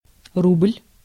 Russian рубль копейка [ˈrublʲ]
Ru-рубль.ogg.mp3